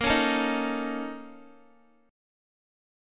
BbmM7bb5 chord